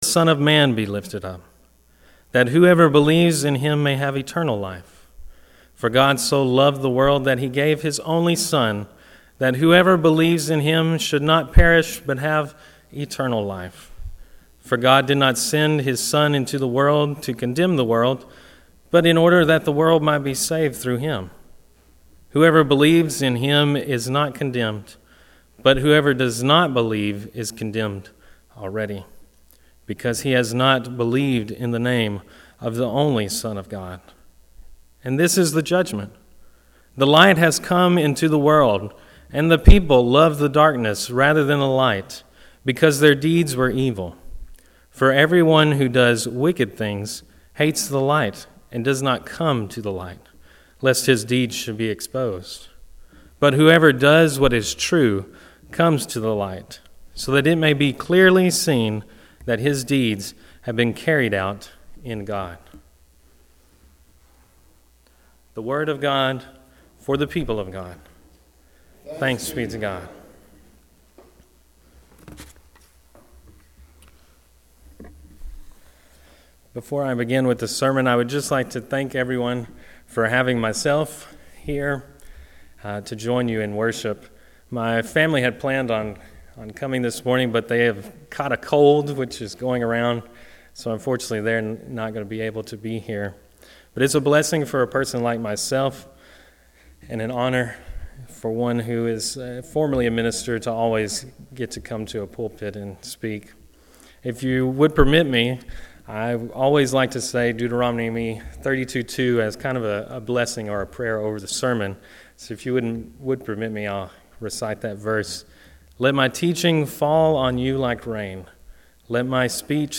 First Presbyterian Church Henderson
Sermon Audio